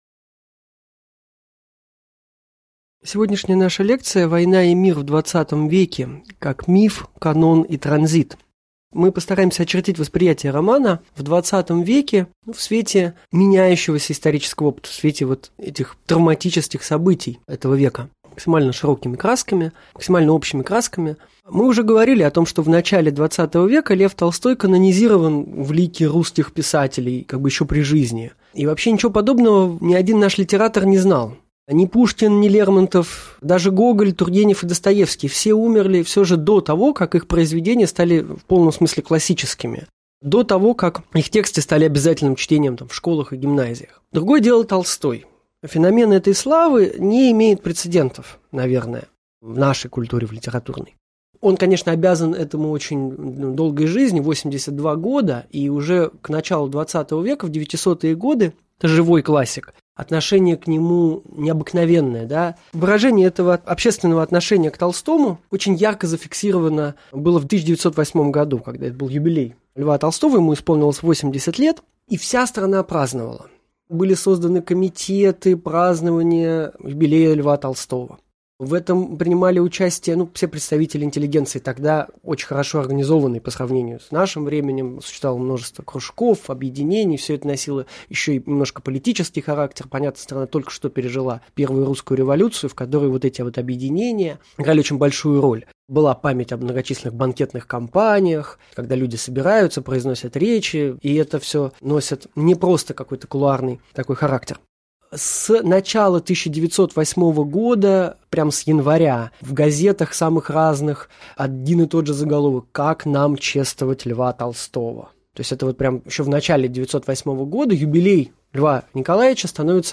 Аудиокнига Лекция «Война и мир» как миф, канон и транзит» | Библиотека аудиокниг